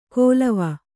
♪ kōlava